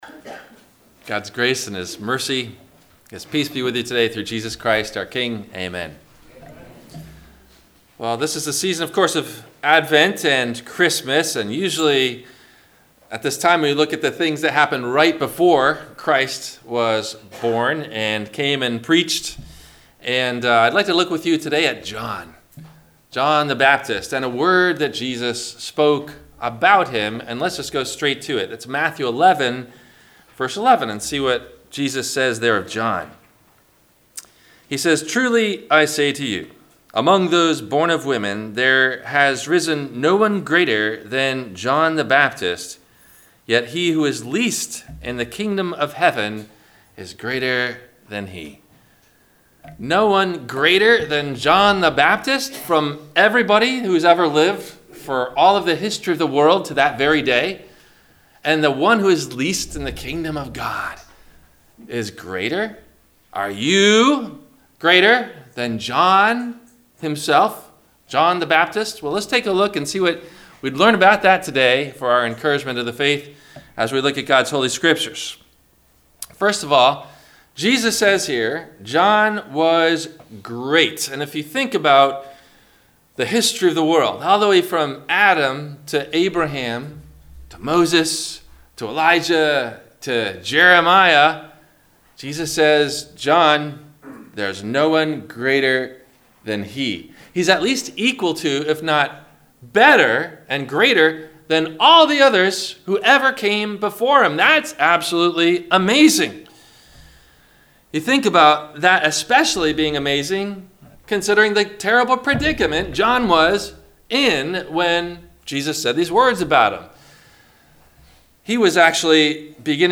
- Sermon - December 22 2019 - Christ Lutheran Cape Canaveral